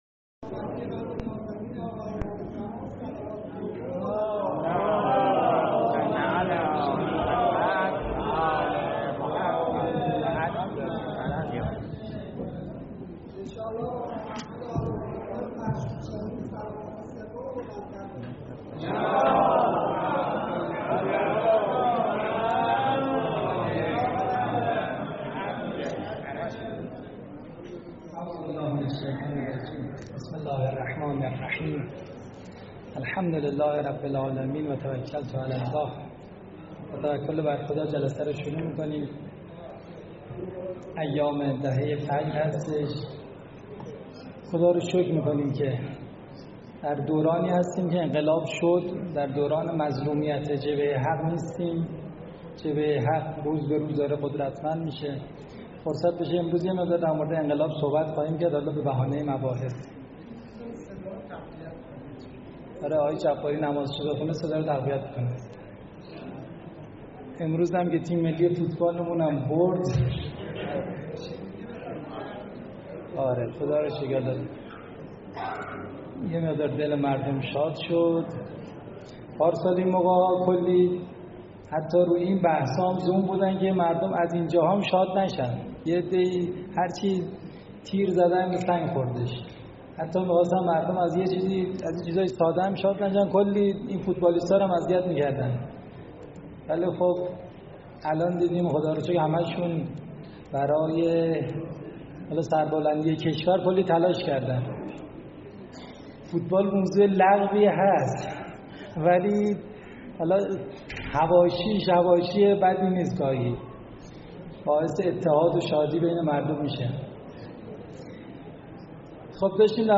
سخنرانی های